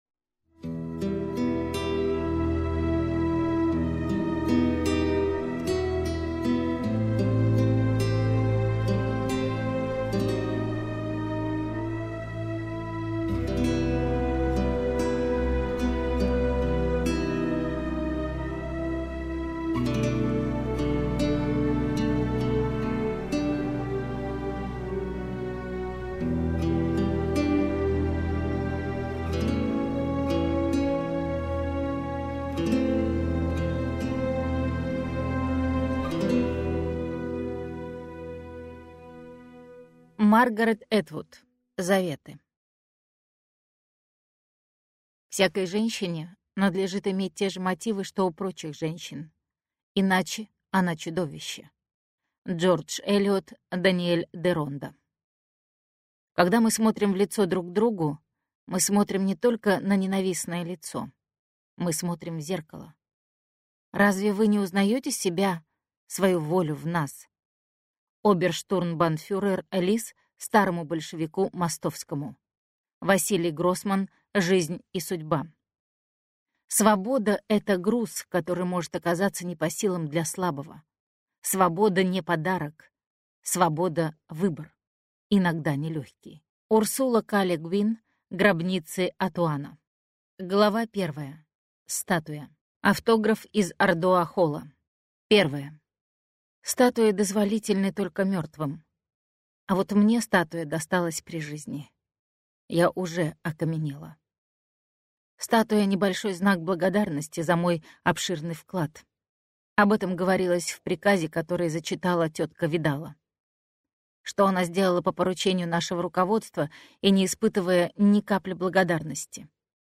Аудиокнига Заветы - купить, скачать и слушать онлайн | КнигоПоиск